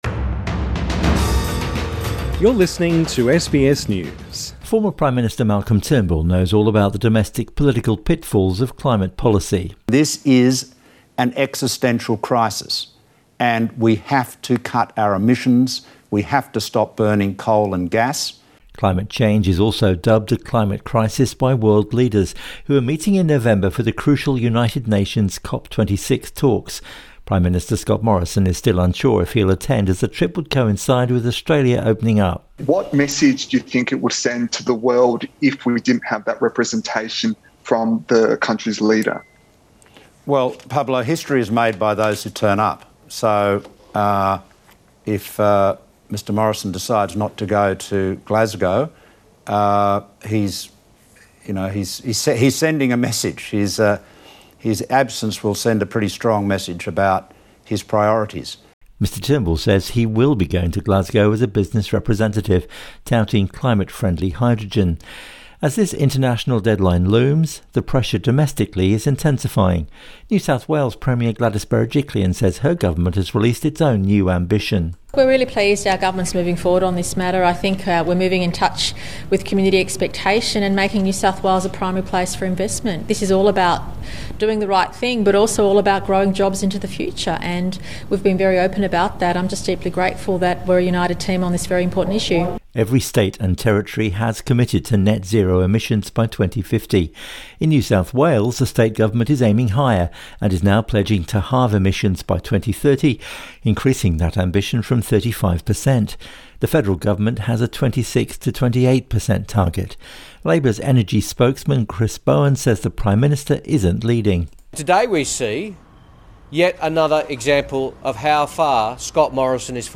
Malcolm Turnbull speaking at the National Press Club Source: AAP